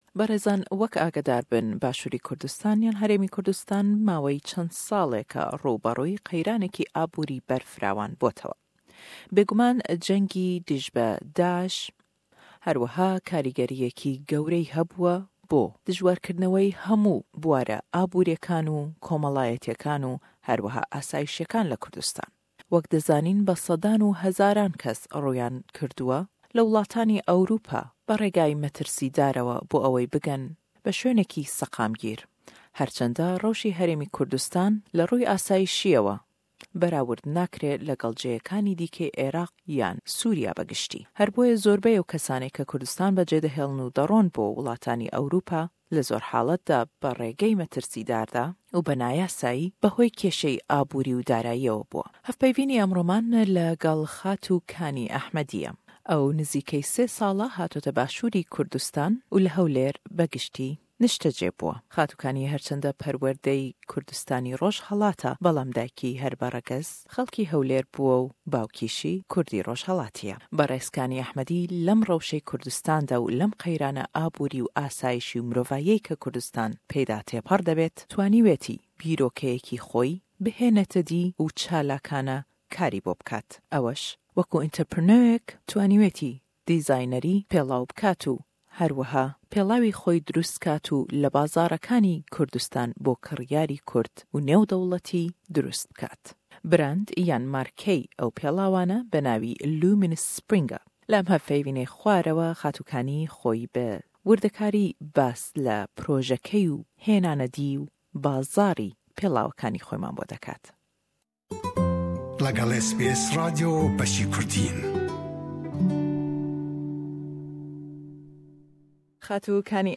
In this interview she tells us why and how she started this business project.